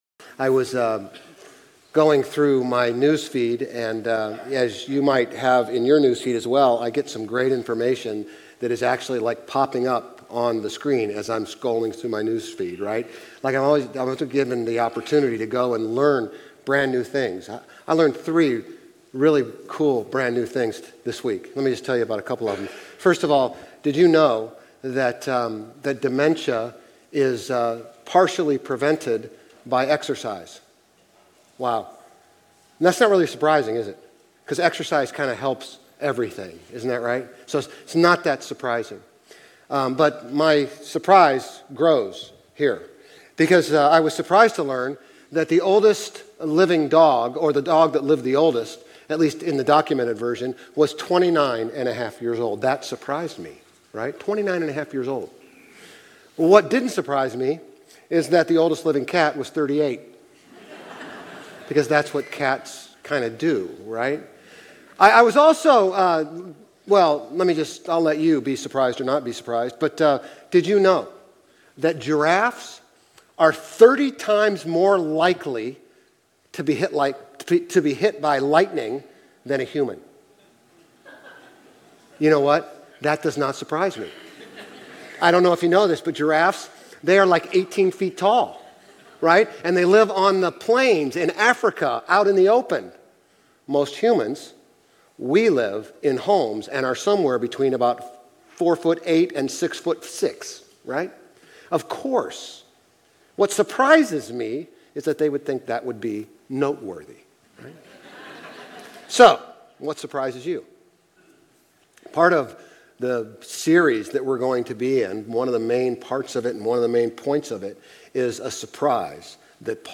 Grace Community Church Old Jacksonville Campus Sermons Galatians 1:1-24 Apr 08 2024 | 00:35:33 1x 00:00 / 00:35:33 Subscribe Share RSS Feed Share Link Embed